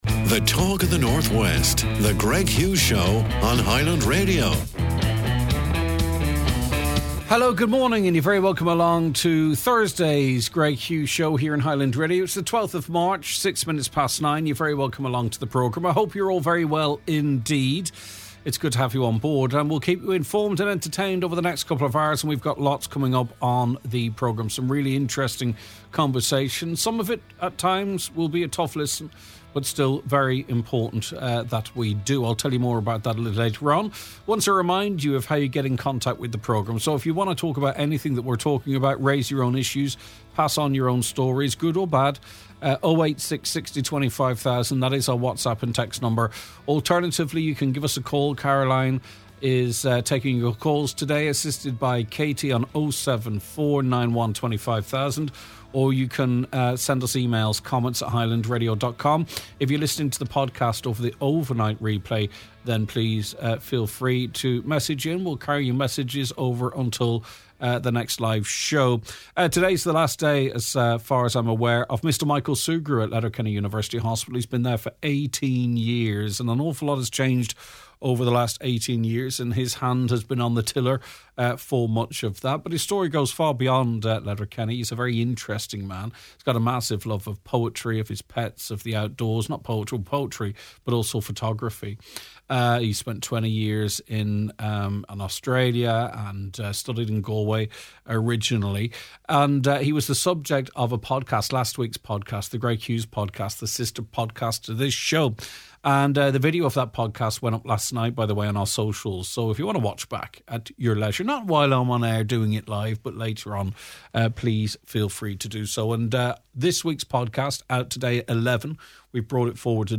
Buncrana Community Hospital: Further Delays Spark Outrage Sinn Féin Chief Whip Pádraig Mac Lochlainn TD joins the show to discuss the latest setback for the Buncrana Community Hospital project.